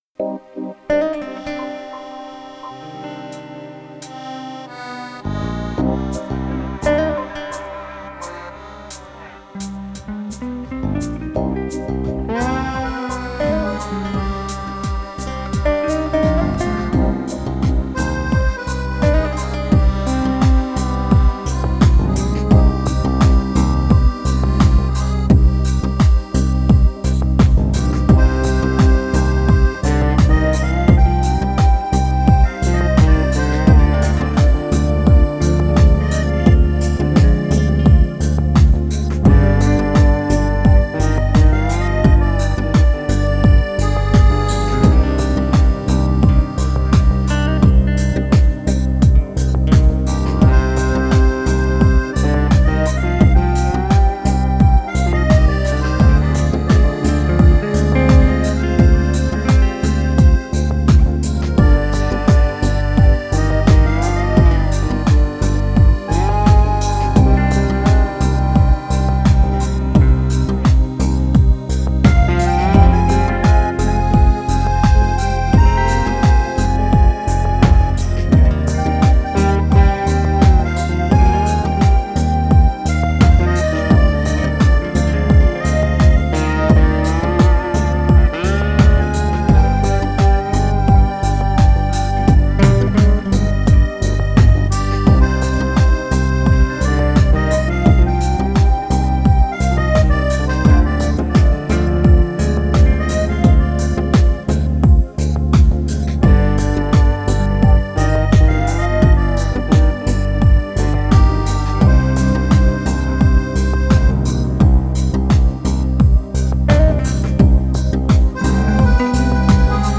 блюз
рок-музыка